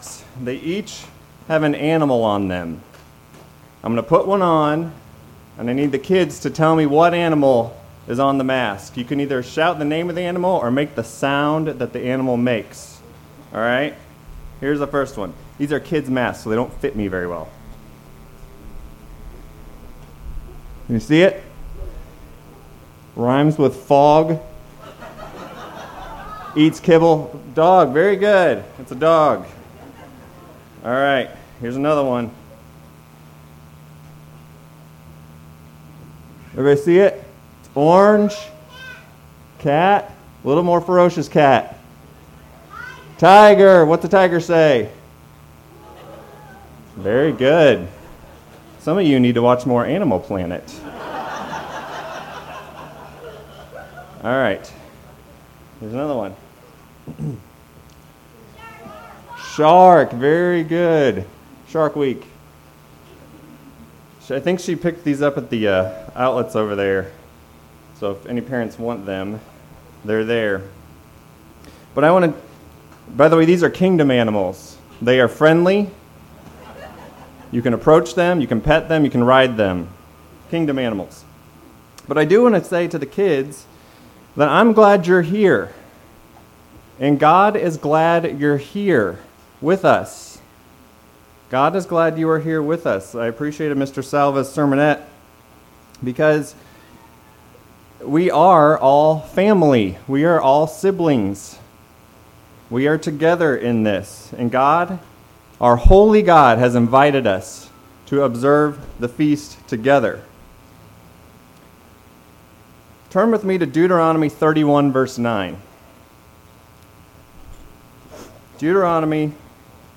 This sermon was given at the Lancaster, Pennsylvania 2020 Feast site.